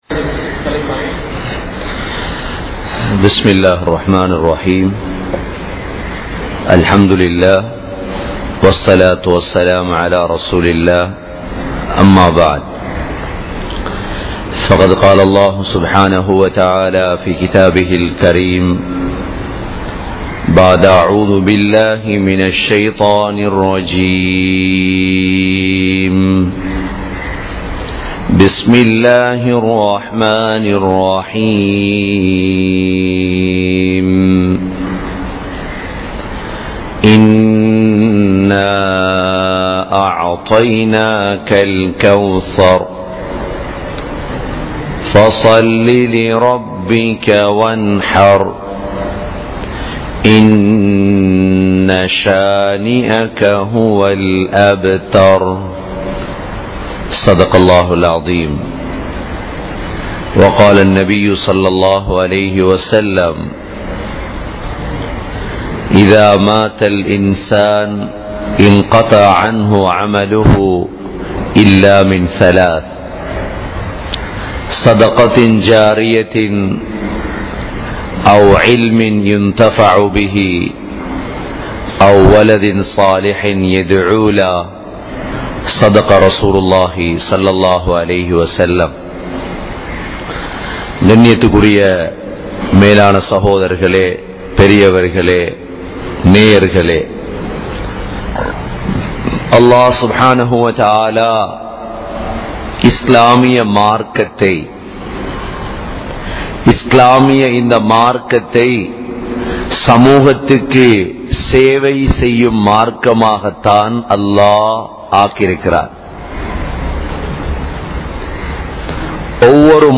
Sadhakathul Jariya (ஸதகதுல் ஜாரிய்யா) | Audio Bayans | All Ceylon Muslim Youth Community | Addalaichenai
Colombo 02, Wekanda Jumuah Masjidh